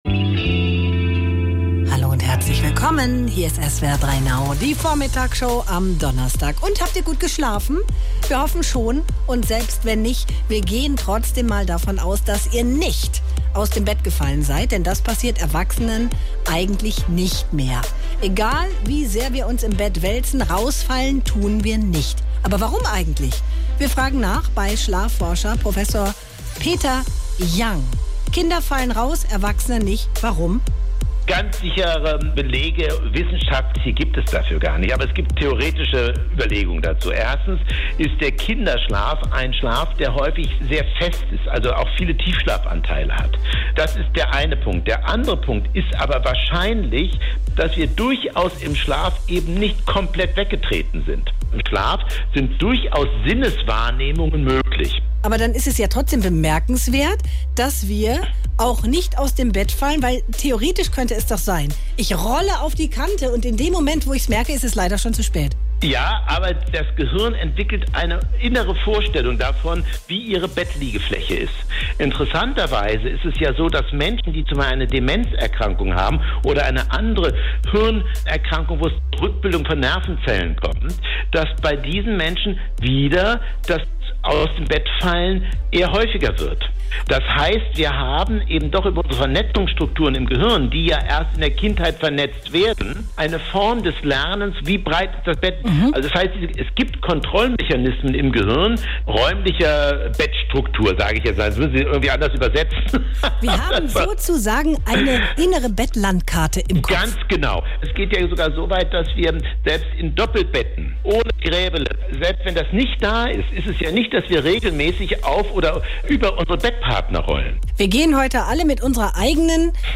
Wieso Erwachsene im Schlaf nicht mehr aus dem Bett fallen, Kinder hingegen schon, hat Schlafforscher